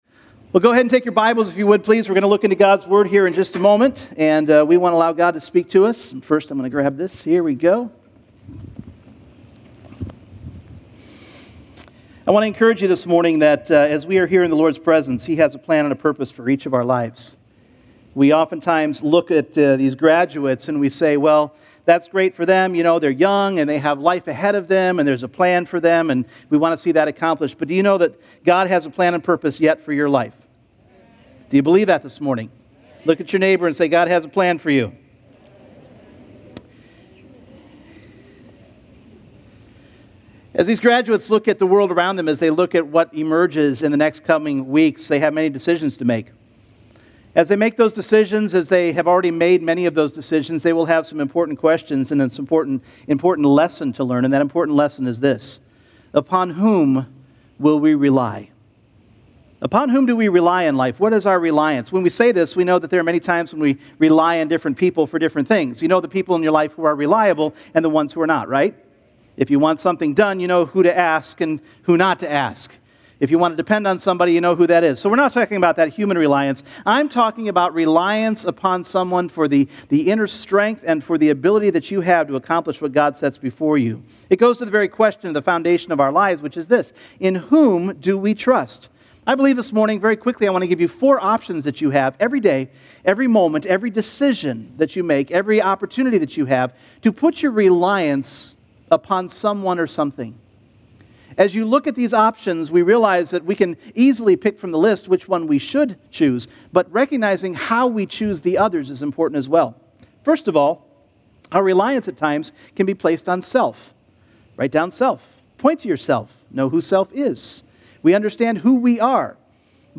This first sermon in a series of teachings on the Holy Spirit will examine the contrast of the ideas of "Fate" and "Faith" as they apply to our reliance upon the Holy Spirit. What is interesting is the change that happens in human behavior when we believe that our free will is less of a factor than fate, and how this determinism affects our ability to truly walk after the Spirit of God's leading.
Service Type: Sunday Morning